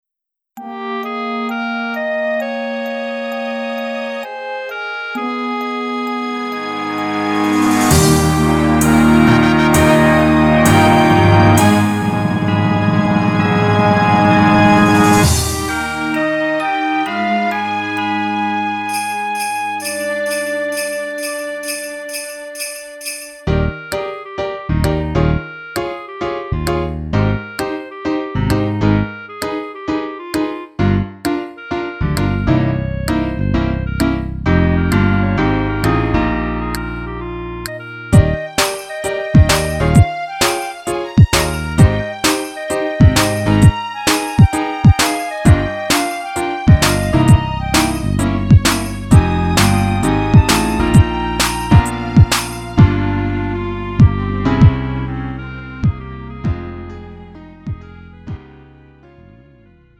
음정 -1키 4:01
장르 가요 구분 Lite MR
Lite MR은 저렴한 가격에 간단한 연습이나 취미용으로 활용할 수 있는 가벼운 반주입니다.